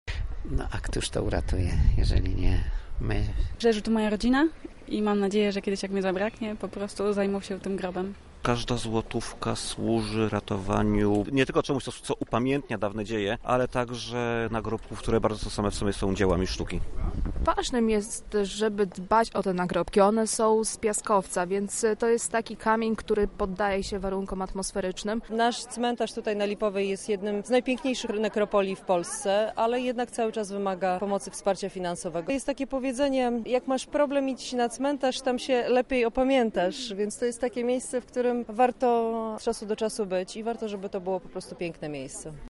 Zapytaliśmy wolontariuszy o to, dlaczego warto angażować się w kwestowanie.
A o tym, jakie znaczenie dla Lublinian może mieć cmentarz przy Lipowej mówi Joanna Mucha.
Na miejscu był nasz reporter.